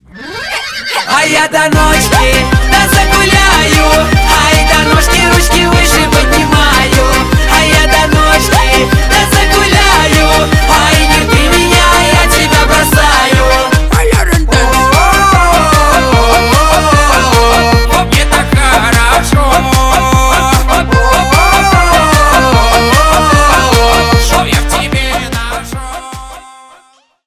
Ремикс # весёлые